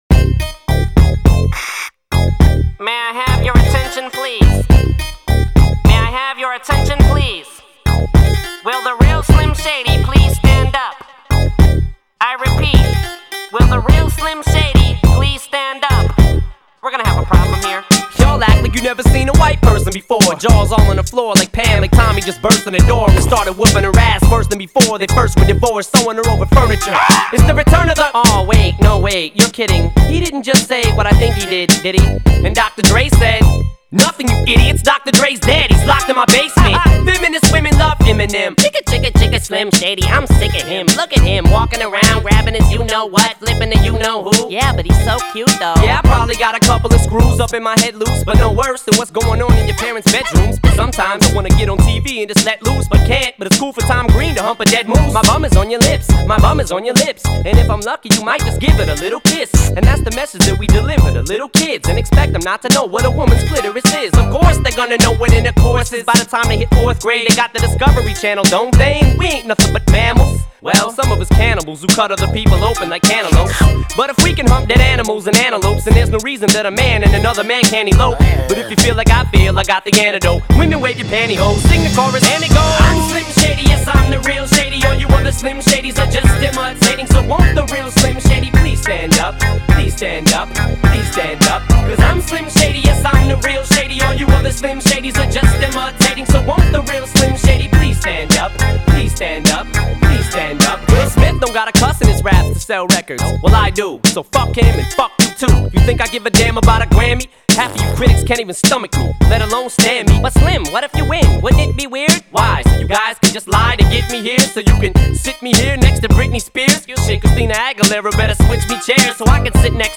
Зарубежный Рэп